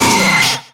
Grito de Pinsir.ogg
Grito_de_Pinsir.ogg.mp3